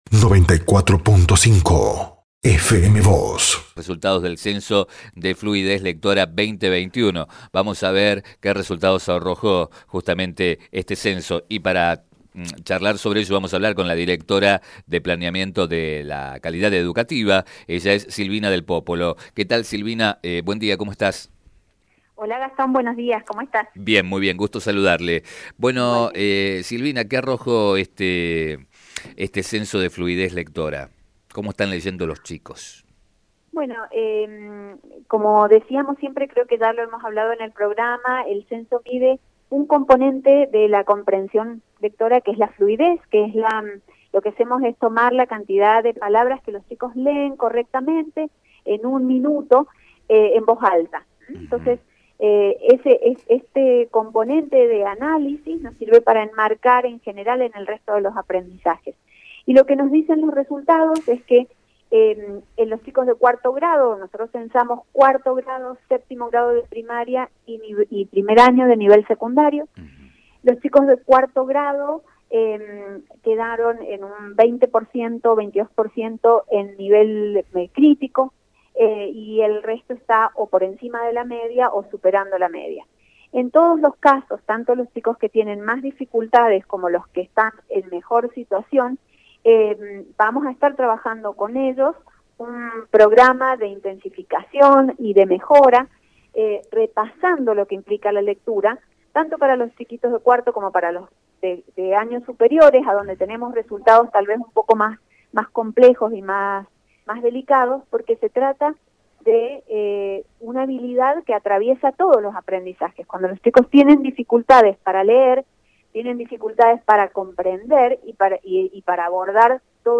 El pasado 15 de marzo comenzó en Mendoza el Censo de Fluidez Lectora, del que participaron más de 900 instituciones educativas. La directora de Planeamiento de la Calidad Educativa, Silvina Del Pópolo, hizo un balance de los datos que brindó el censo.